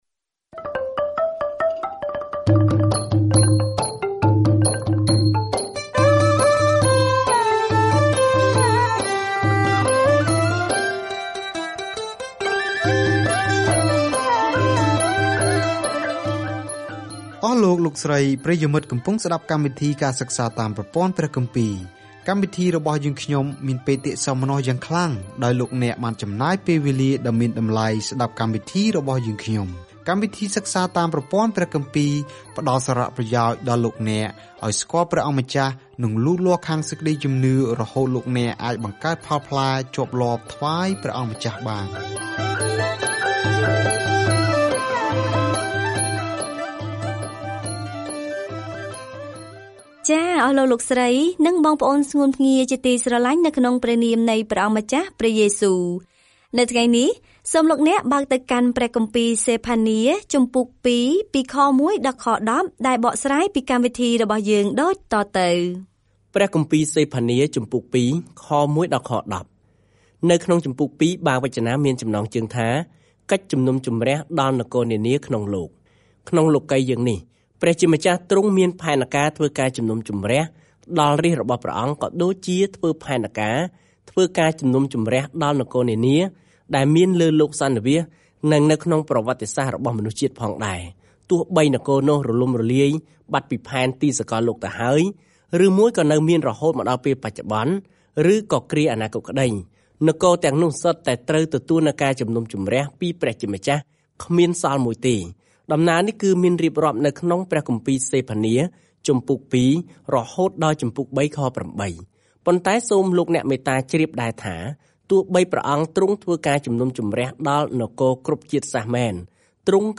សេផានាបានព្រមានអ៊ីស្រាអែលថា ព្រះនឹងវិនិច្ឆ័យពួកគេ ប៉ុន្តែប្រាប់ពួកគេផងដែរថាទ្រង់ស្រឡាញ់ពួកគេប៉ុណ្ណា ហើយថ្ងៃណាមួយទ្រង់នឹងរីករាយនឹងពួកគេដោយការច្រៀង។ ការធ្វើដំណើរប្រចាំថ្ងៃតាមរយៈសេផានា ពេលអ្នកស្តាប់ការសិក្សាជាសំឡេង ហើយអានខគម្ពីរដែលជ្រើសរើសពីព្រះបន្ទូលរបស់ព្រះ។